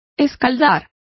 Complete with pronunciation of the translation of blanch.